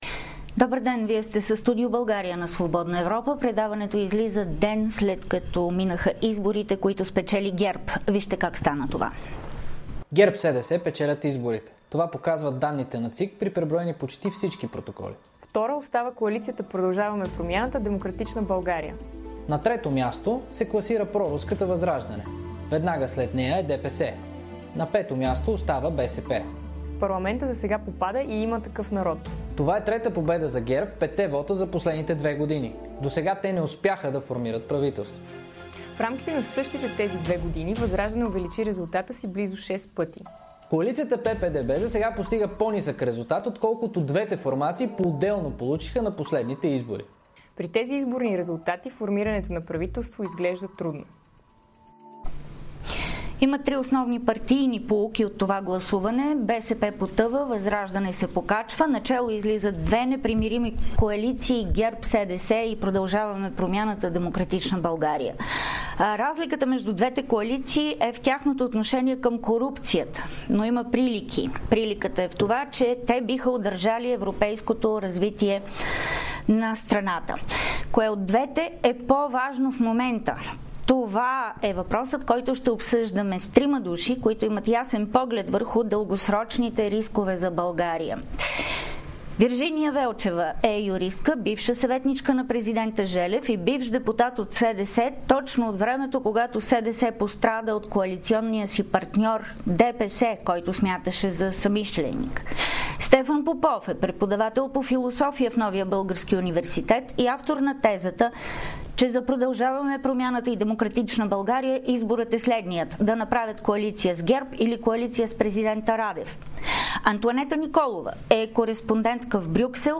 Прилики и разлики между двете коалиции, събрали най-много гласове. Разговор с трима анализатори